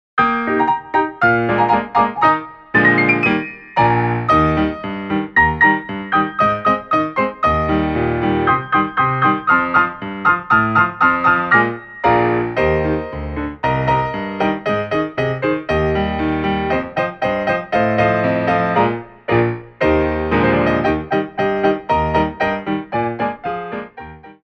Coda
2/4 (8x8)